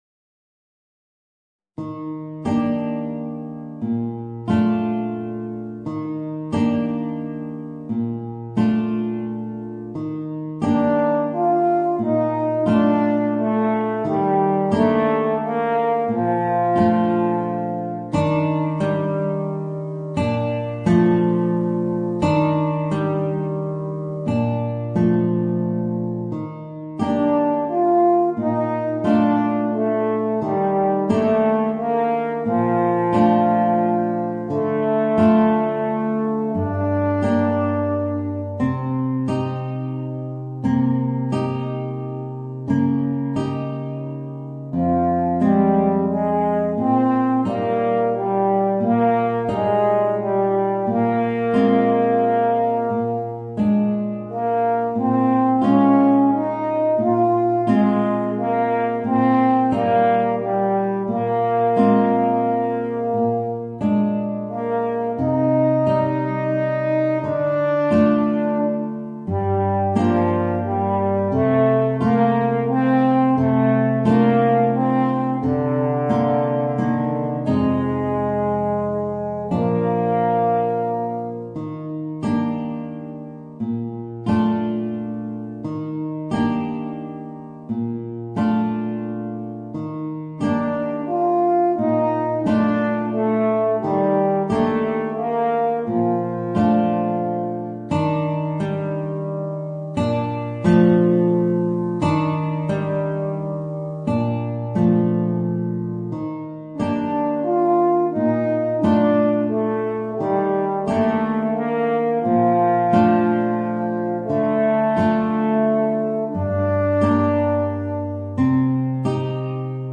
Voicing: Horn and Guitar